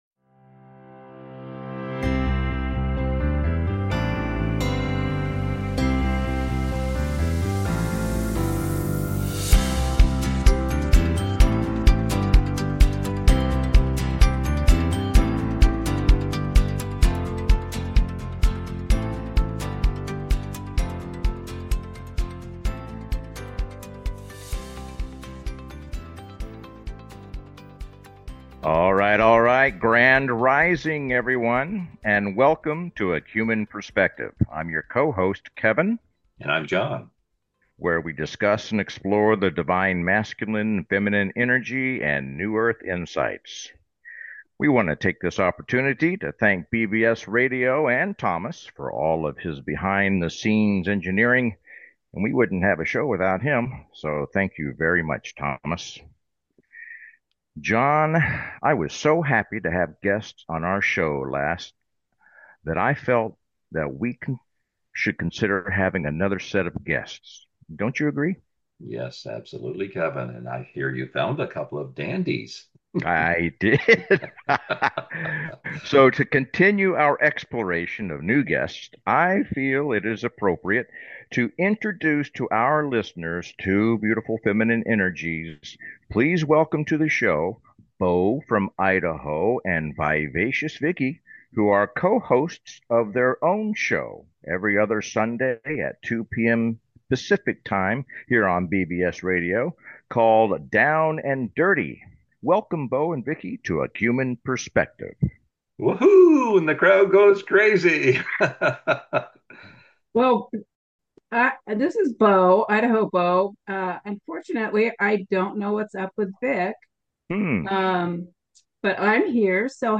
Talk Show Episode, Audio Podcast
The show is structured to welcome call-ins and frequently features special guests, offering a diverse range of perspectives.